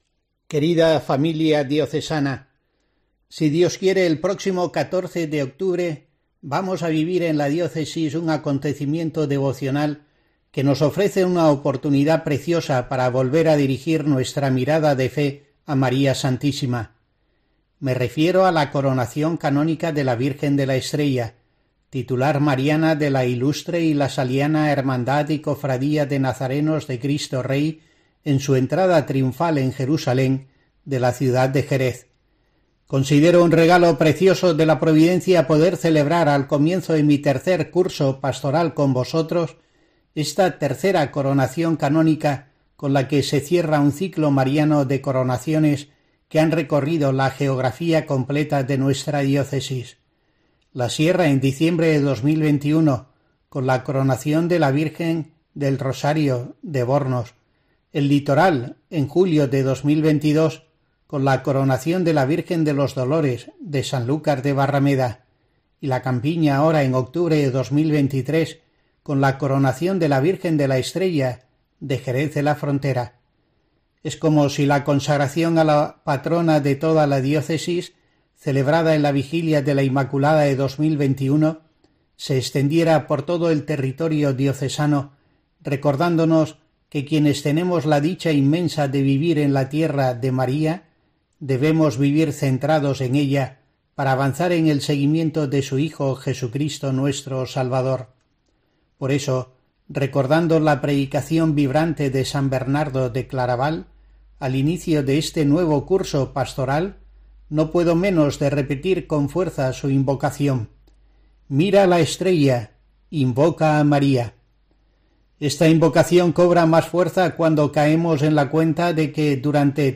Escucha aquí la reflexión semanal del obispo de Asidonia-Jerez para la programación religiosa de COPE en la que se hace eco de ello